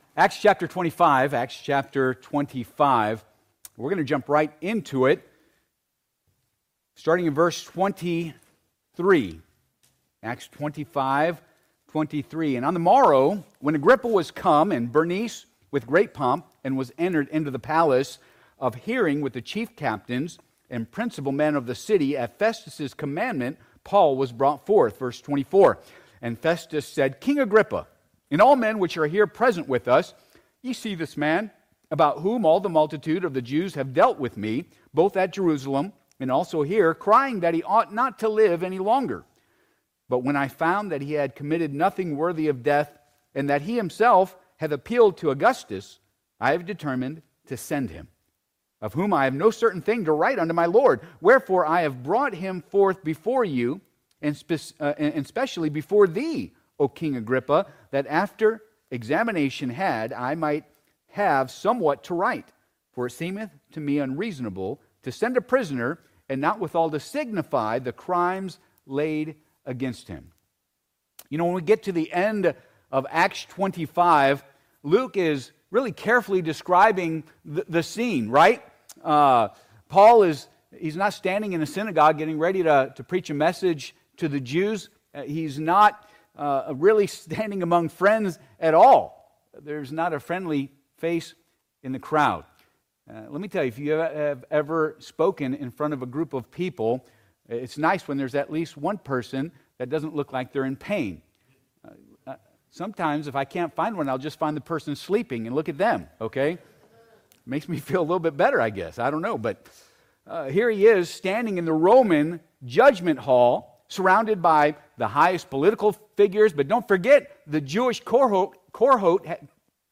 Acts Passage: Acts 25:23 - 26:32 Service Type: Sunday AM « Micah’s Imitation Worship cont.